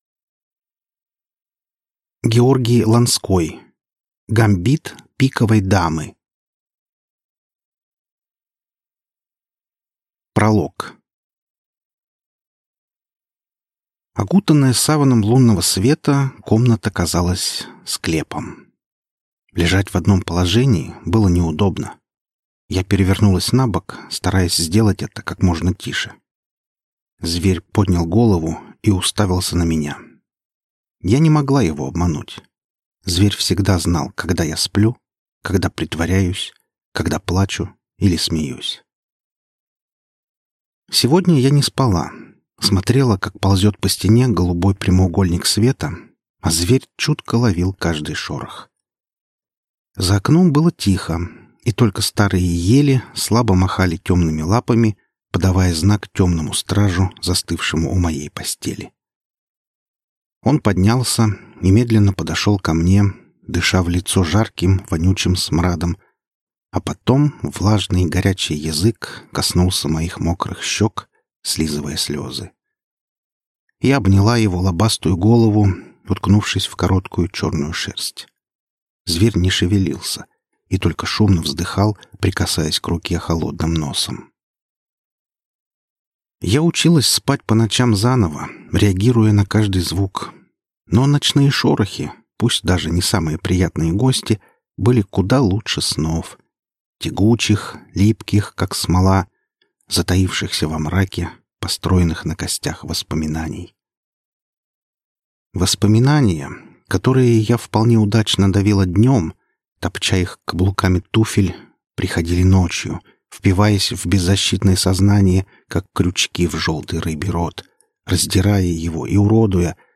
Аудиокнига Гамбит пиковой дамы | Библиотека аудиокниг